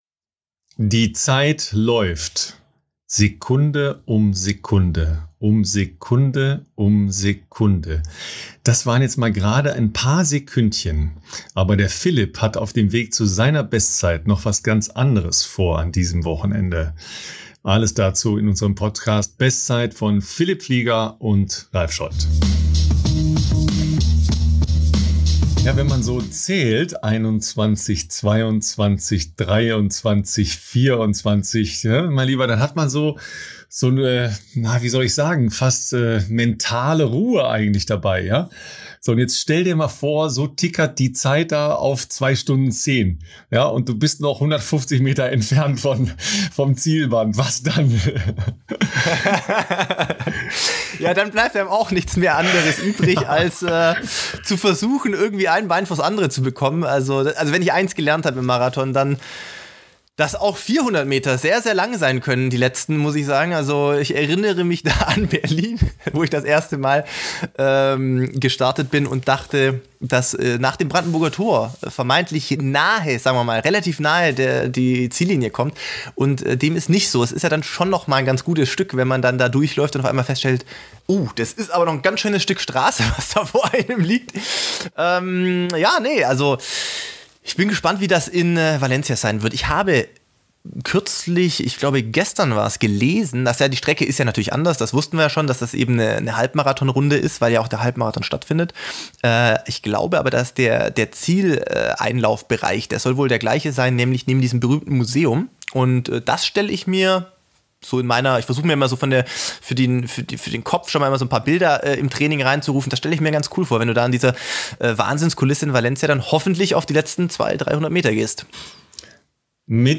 Wenn sich der Marathonprofi und der Journalist unterhalten geht es also natürlich um’s Laufen und das aktuelle Geschehen in der Ausdauersportwelt. Es geht aber auch um Behind-the-Scenes-Einblicke in ihr tägliches Leben für den Sport, ihre unterschiedlichen Erlebniswelten und die damit verbundenen Blickwinkel